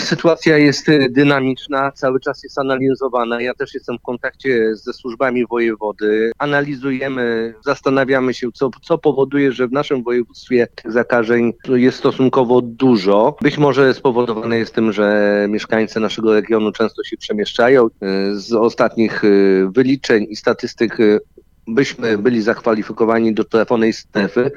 Mówi Wojciech Kossakowski – poseł Prawa i Sprawiedliwości z Ełku.